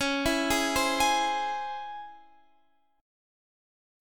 DbmM7 chord